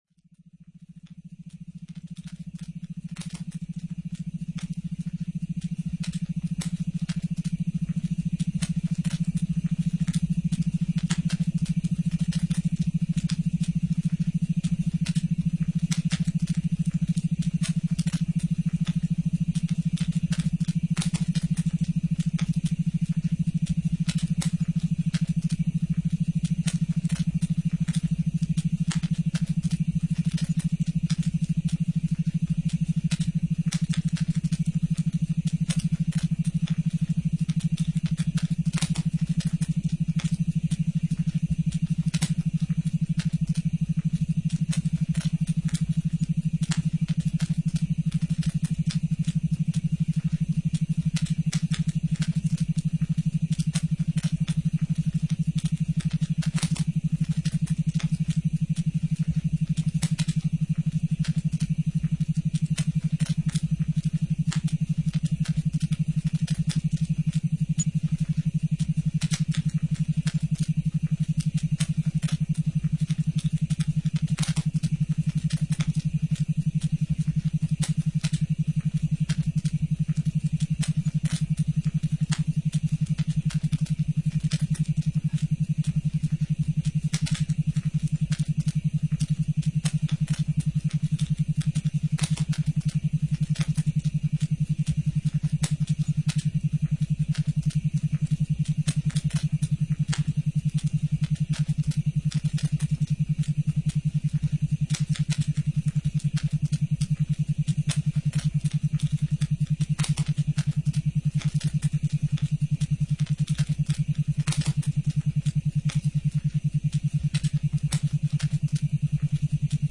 45 Mins session with a combination of the sounds of a crackling night fire along with a series of hypnotic Alpha waves that entrain your mind toward a deep meditation.
Each session is designed using the latest methods of delivering effective MP3 brainwave entrainment, binaural beats and nature sounds to you.
preview-alpha-fire.mp3